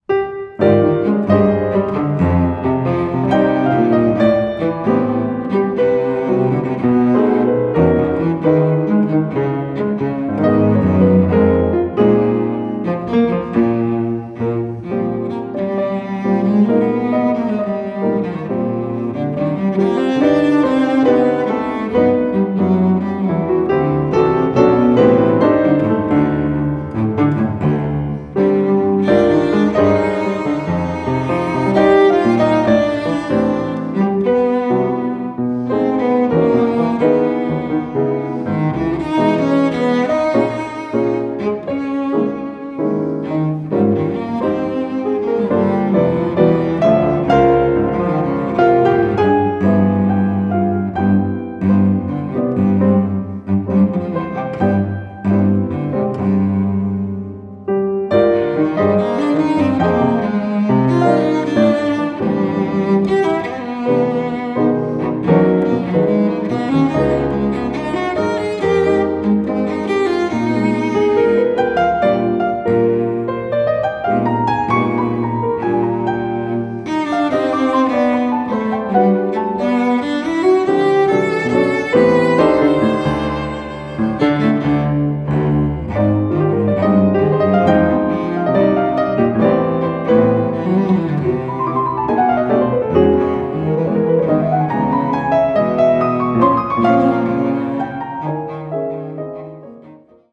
Voicing: String Duet